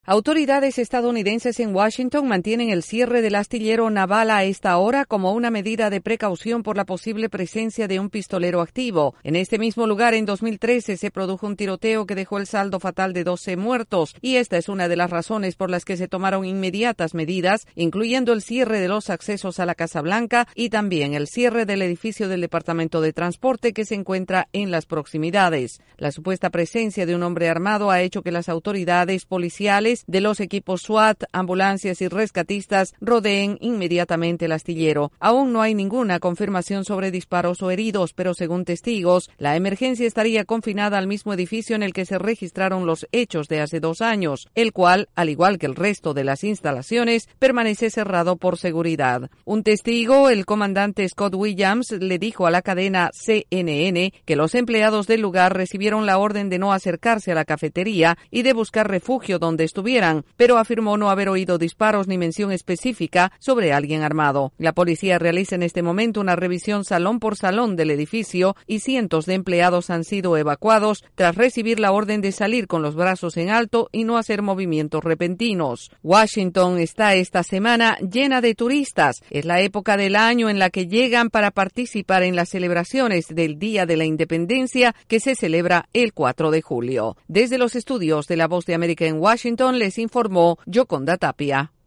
La posible presencia de un hombre armado en el Astillero Naval en Washington obliga a las autoridades al cierre inmediato de las instalaciones y la evacuación controlada de sus empleados, siguiendo el protocolo de emergencia. Desde la Voz de América en Washington informa